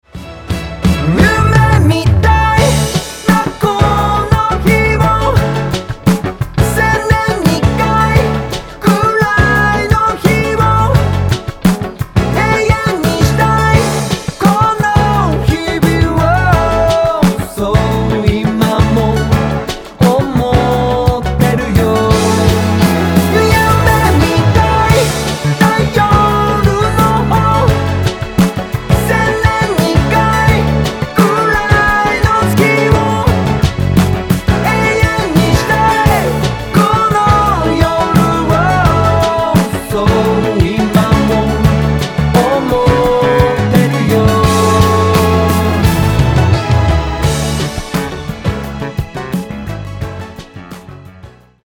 мужской вокал
веселые
Pop Rock
J-Pop
j-rock
Японский Поп-Рок